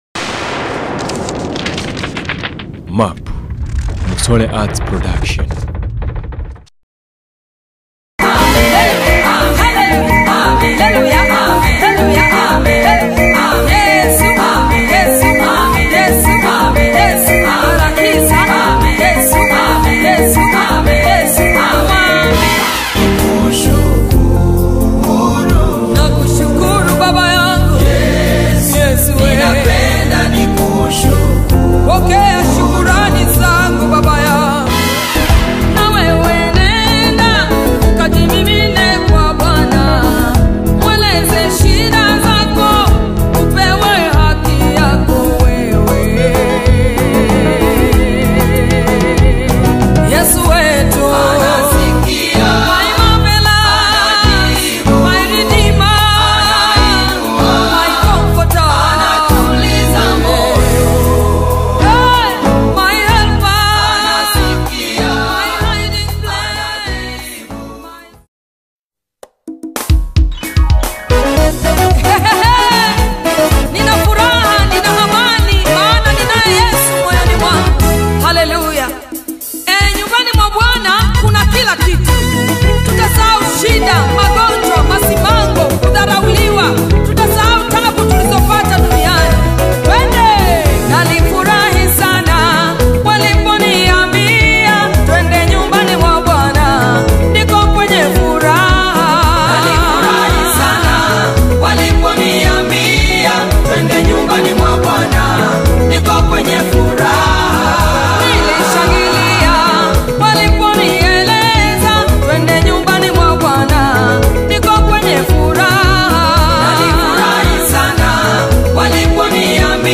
GOSPEL
joyful and spirit-lifting song